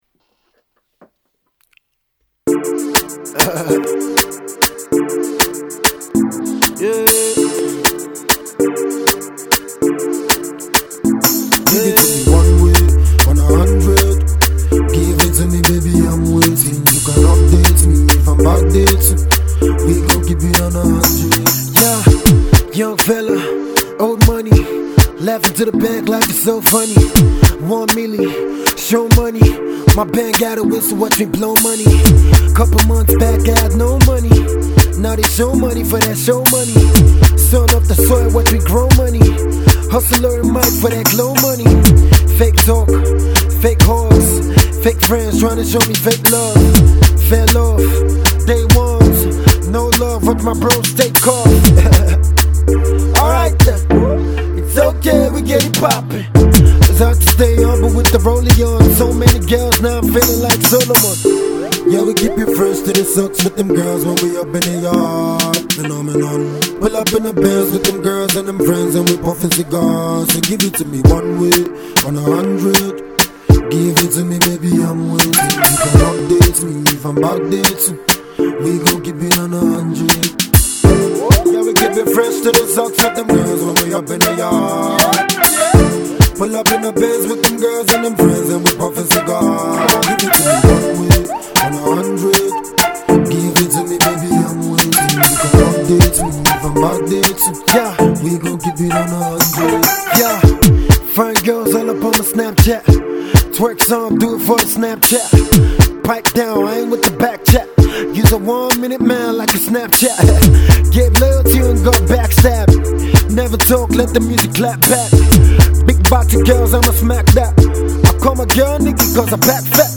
rappers